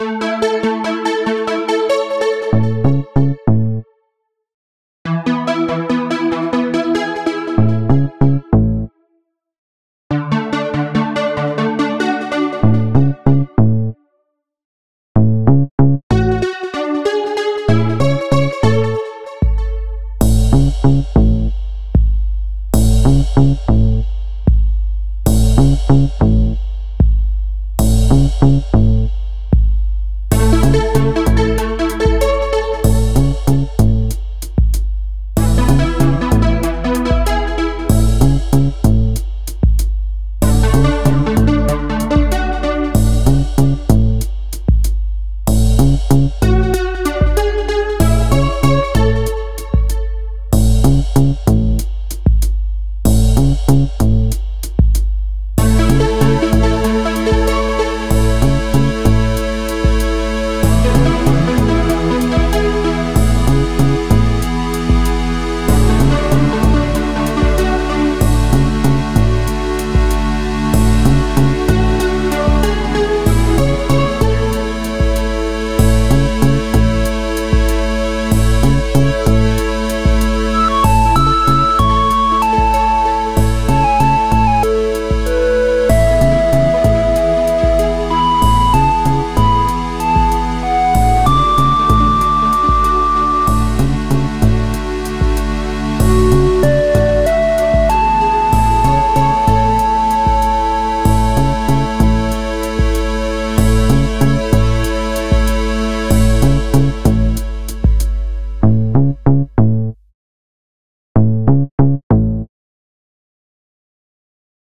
A simple ambient track with a slow beat in A Major.